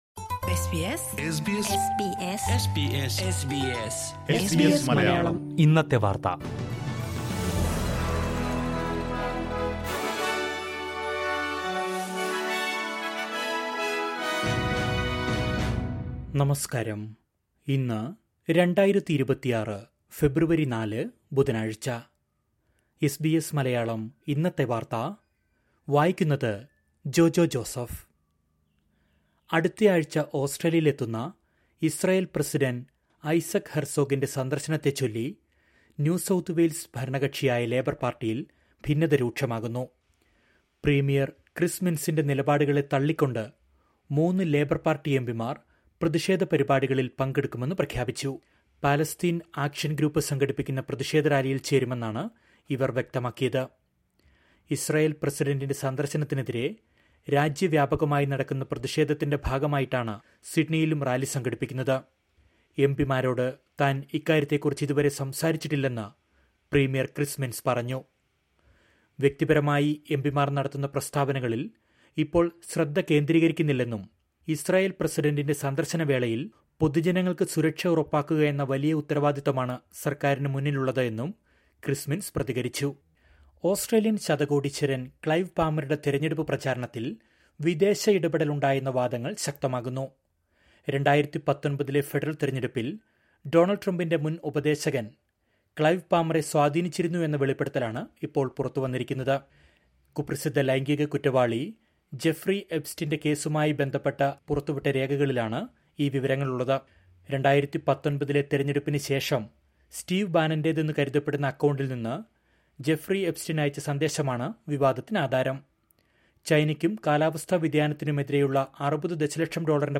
2026 ഫെബ്രുവരി നാലിലെ ഓസ്ട്രേലിയയിലെ ഏറ്റവും പ്രധാന വാർത്തകൾ കേൾക്കാം...